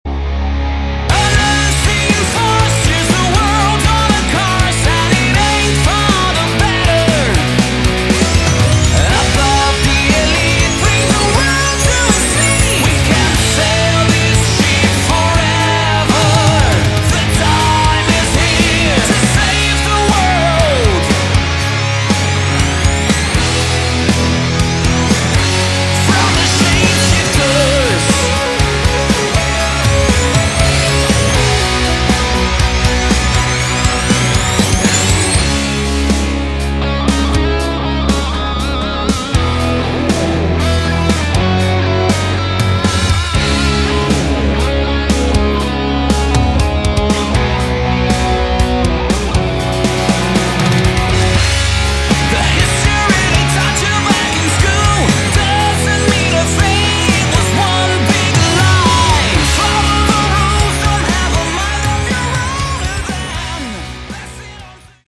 Category: Hard Rock
lead vocals